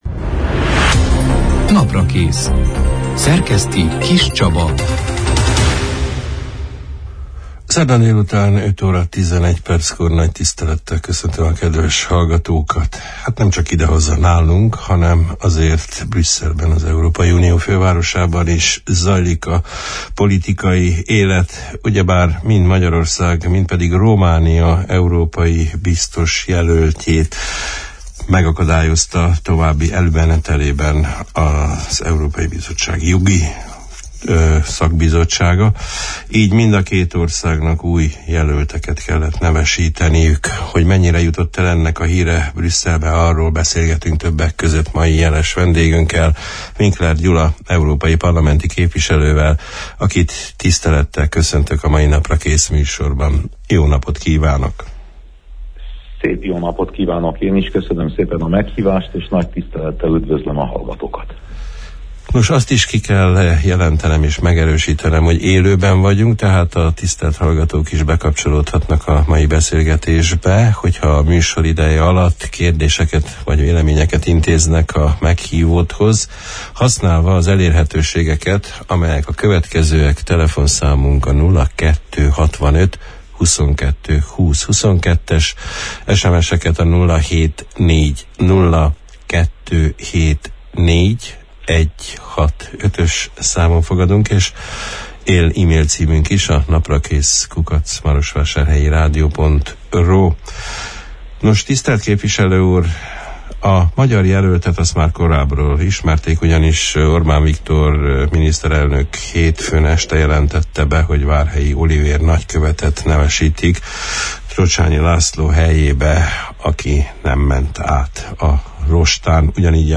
Új személyeket nevezett meg Románia és Magyarorszég is úniós biztosjelölteknek, miután két korábbi javaslatuk a jogi bizottság elutasításába ütközött. Az október 2 – án, szerdán elhangzott élő Naprakész műsorban arról beszélgettünk meghívottunkkal, hogy milyen esélyeik vannak az új jelölteknek, változhat – e a nekik javasolt hatáskör, milyen további forró témák szerepelnek napirenden az Európai Parlamentben, ezekben a zavaros időkben, hogyan alakulhatnak az uniós támogatások az itthon gazdálkodók számára. Vendégünk Winkler Gyula európai parlamenti képviselő volt.